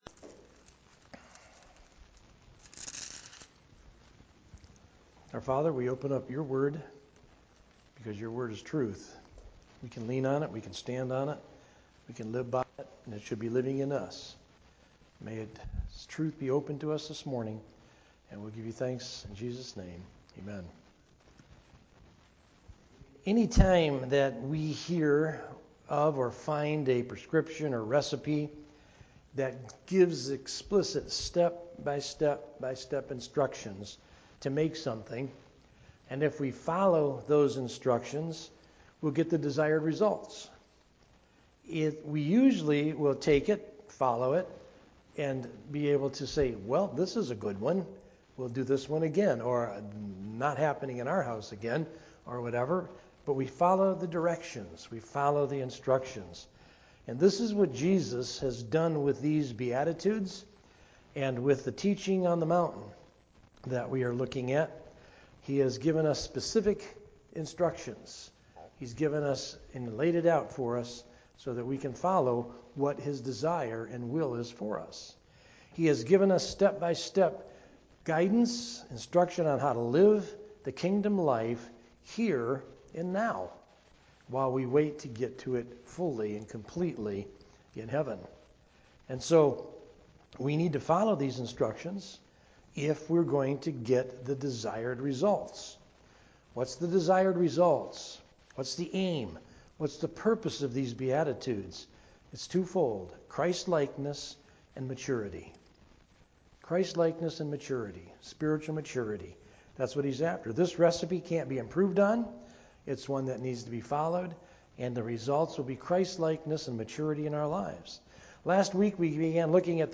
Sermon Audio | FCCNB